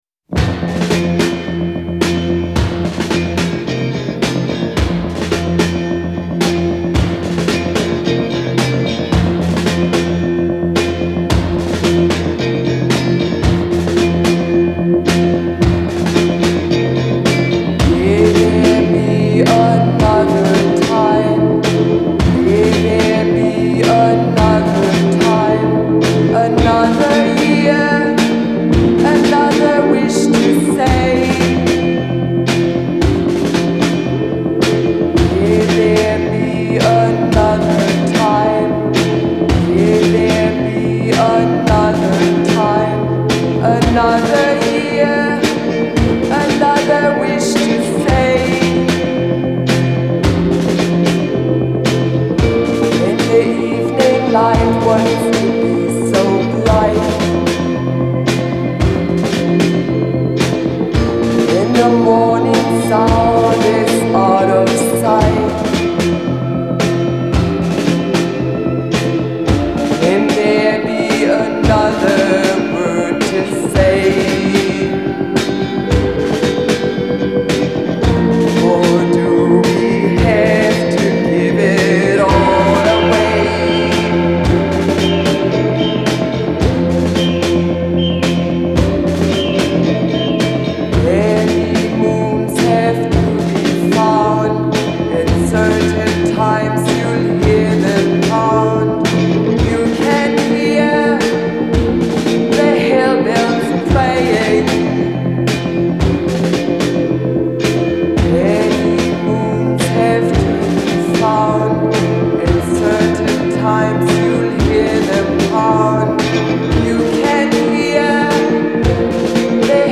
Genre : Punk, New Wave